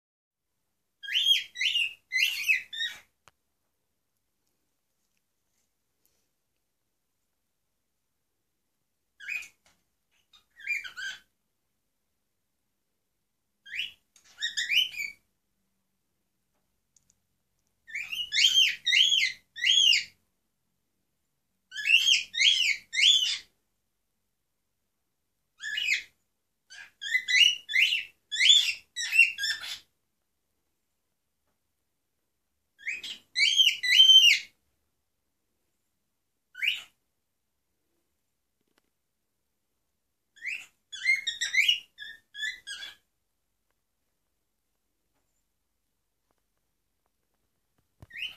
Unsere Nymphensittiche
Zu unserer Überraschung kann Fritzi schön singen (Fritzi1.mp3,